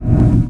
metabolize_success.wav